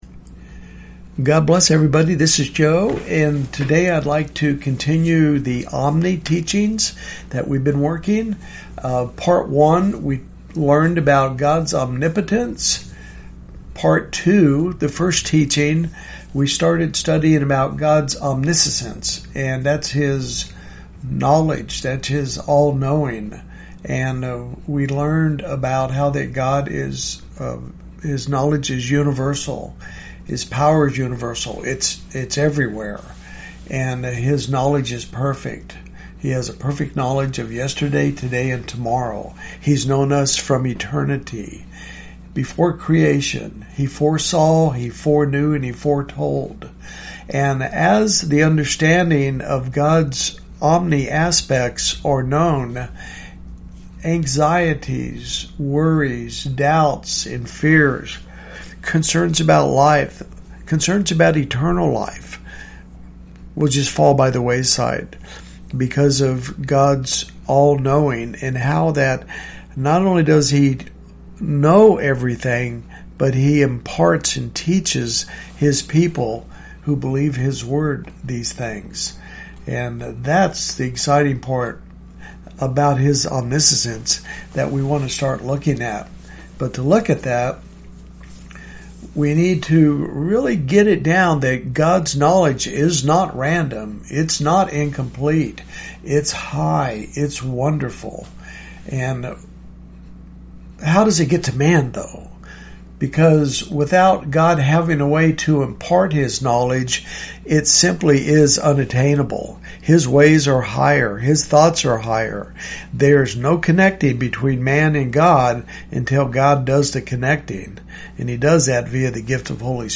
Audio Teaching